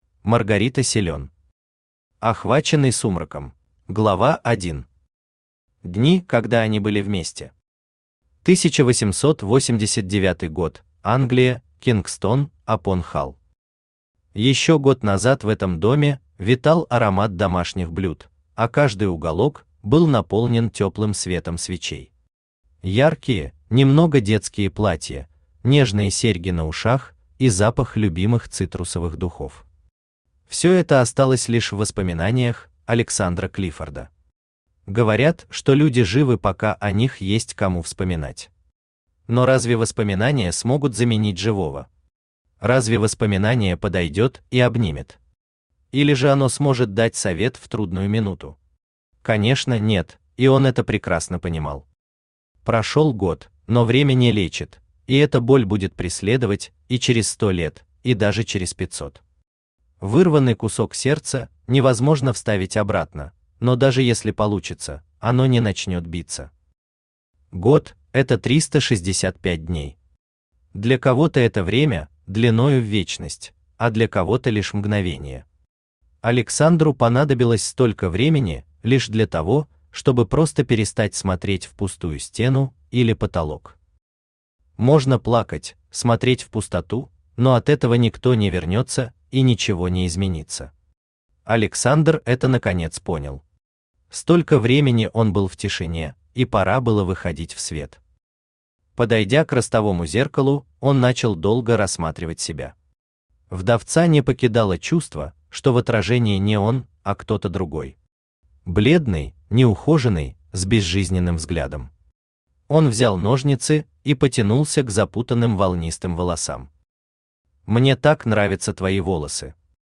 Aудиокнига Охваченный сумраком Автор Маргарита Силен Читает аудиокнигу Авточтец ЛитРес.